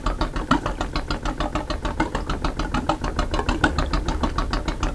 Moteur à combustion interne
Bruit du moteur à combustion interne 4 temps au ralenti. Biplan, hélicoptère, moto, voiture classique, son polyvalent.